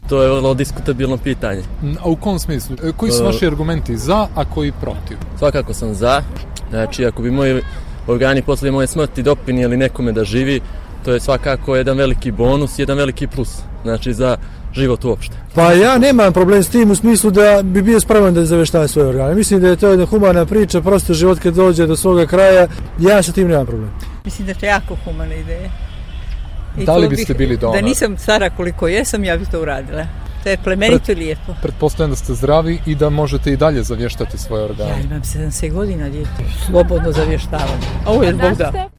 Pitali smo i građane Podgorice da li razmišljaju o tome da postanu donori organa i šta ih motiviše da to učine: